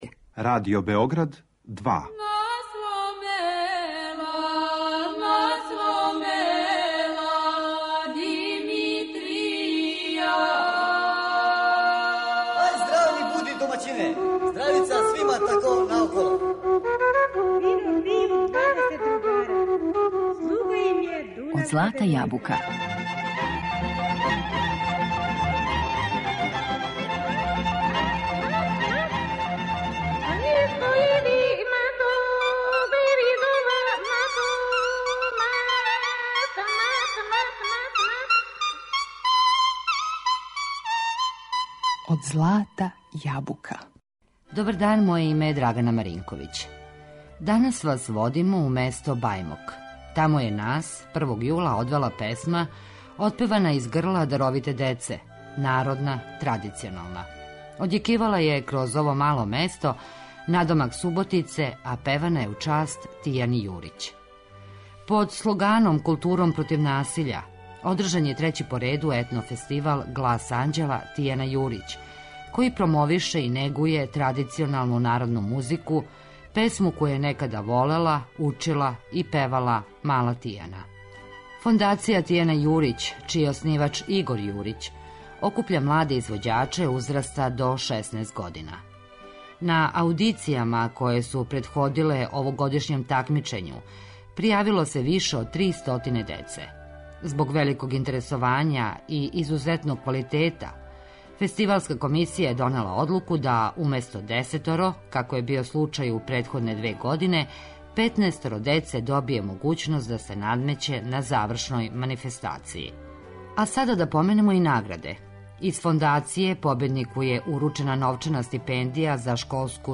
У емисији слушамо и документарни снимак забележен на такмичарској вечери.